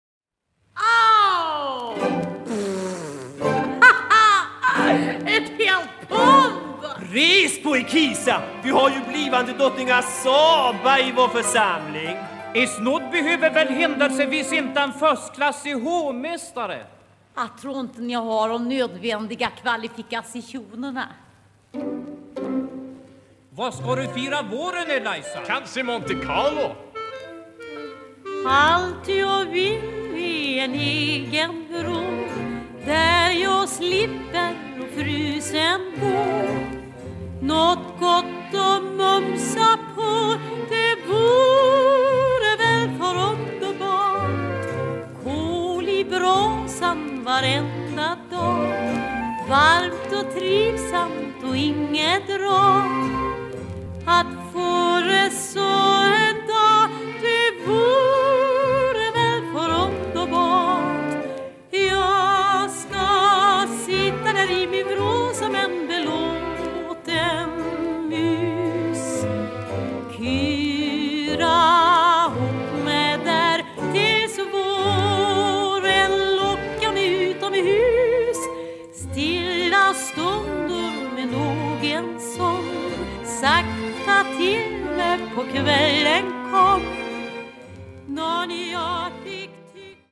Original Swedish Cast Recording